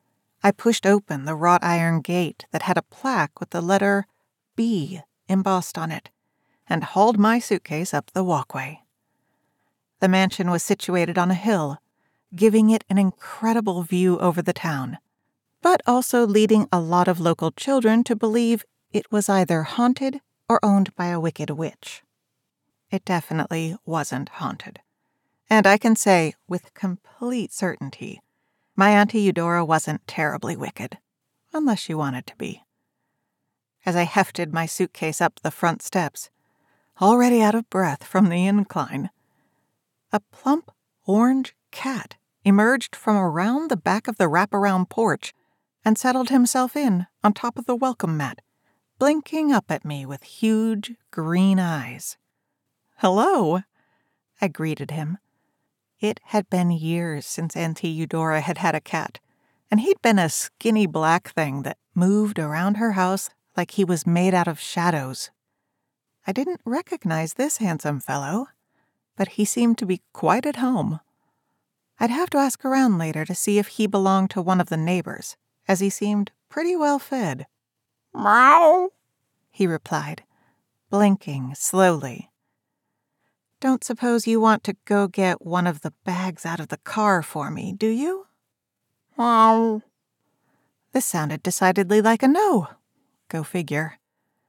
• Custom Built Double-Walled Studio
• Neumann TLM102 Microphone
Cozy Mystery 1st Person
Cozy-Mystery-1st-Person-Fiction.mp3